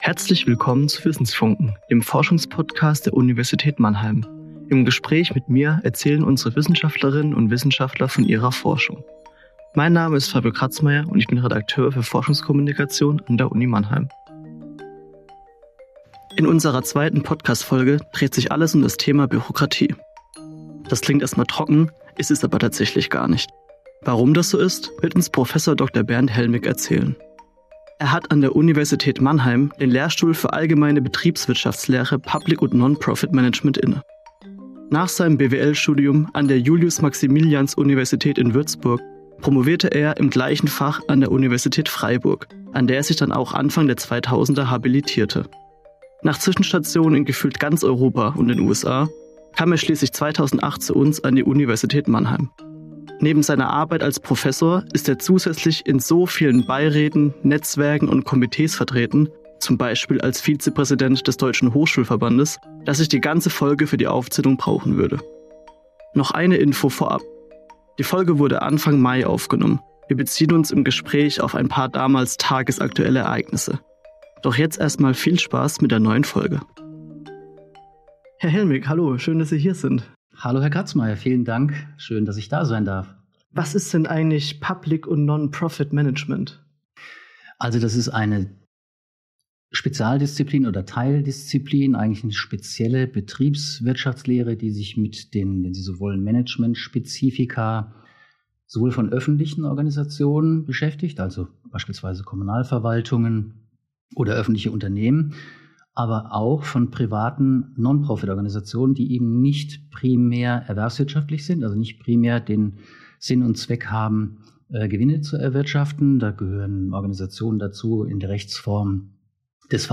Ein Gespräch über Verwaltungsmodernisierung, politische Realitäten – und was man vom Tennis fürs Public Management lernen kann.